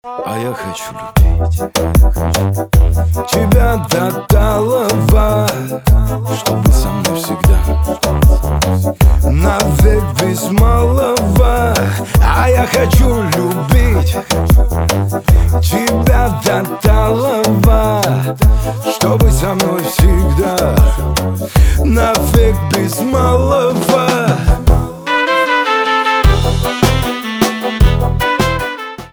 кавказские
битовые , труба
романтические , барабаны